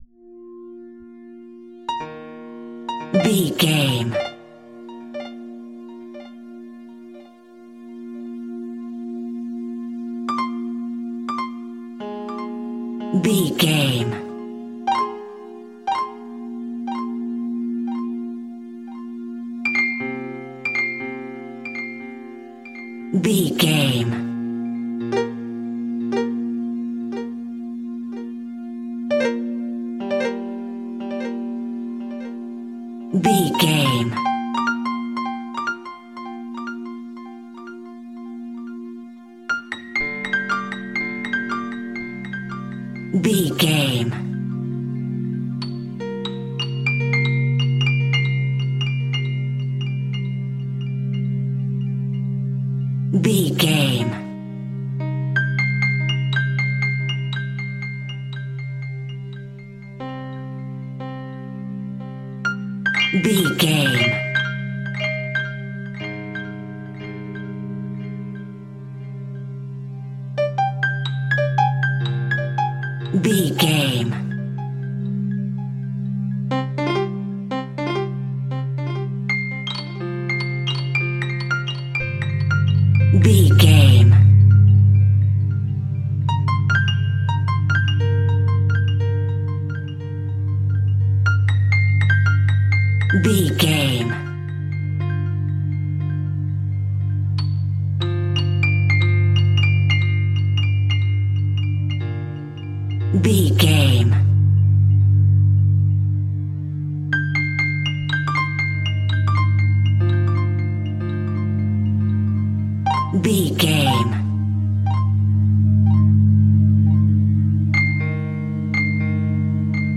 Ionian/Major
B♭
Slow
folk music
World Music